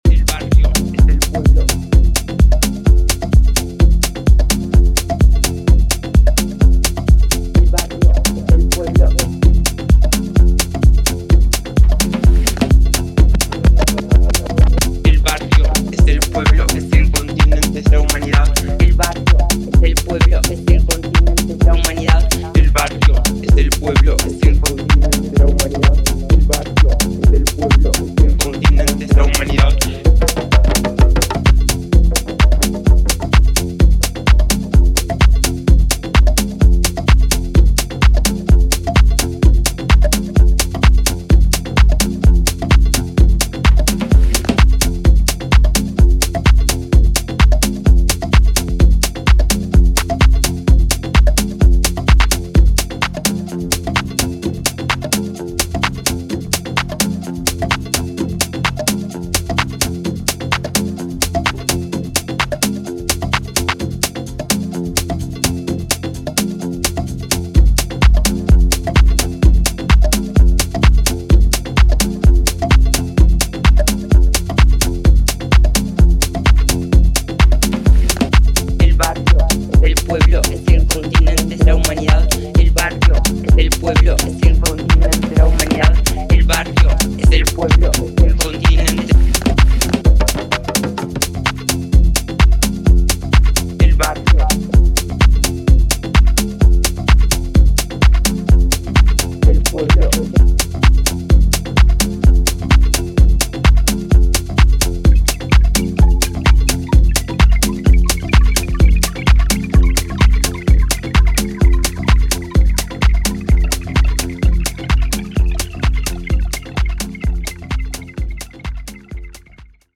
A面のオリジナルはパーカッションやベースラインでアーシーなムードを醸す、ヒプノティックなミニマル・ハウス。